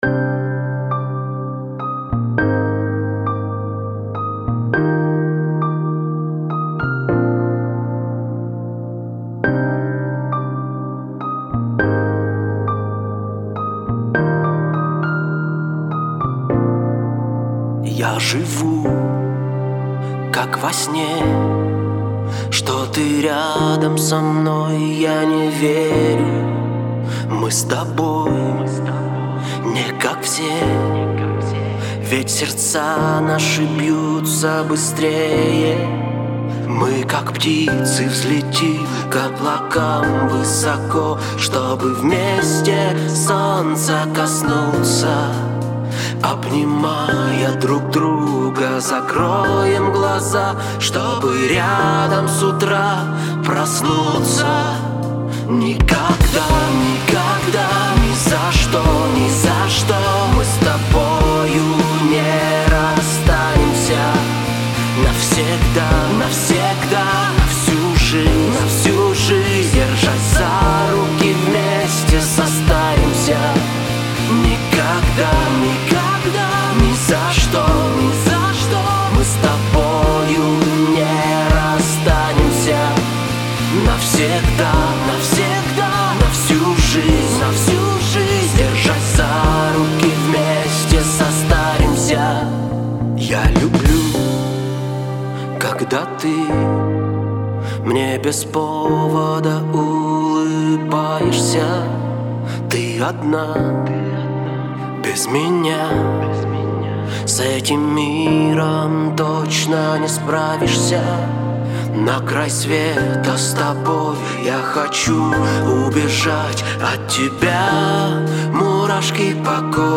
где нежные мелодии переплетаются с громогласными риффами
Вокал, гитара
Бас-гитара
Гитара
Барабаны